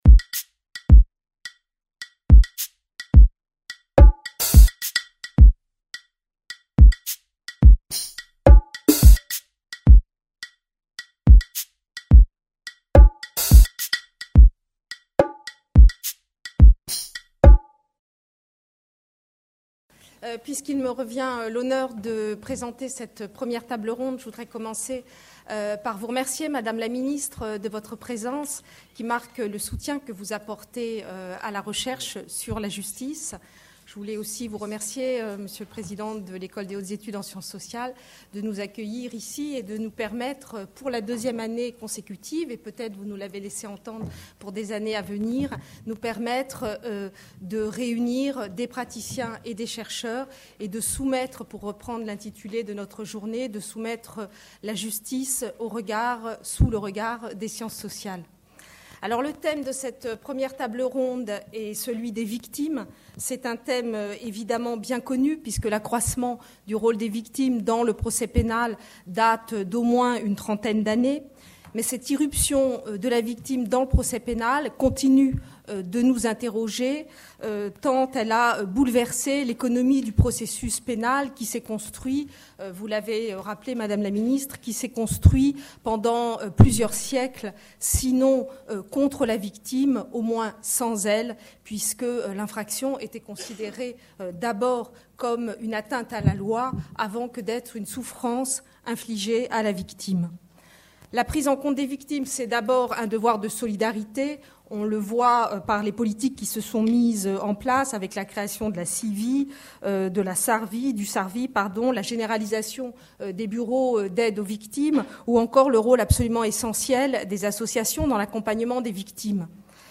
Table ronde 1: Les voies judiciaires pour la victime : action collective et action individuelle | Canal U
La justice sous le regard des sciences sociales Journée organisée par le ministère de la Justice et l'EHESS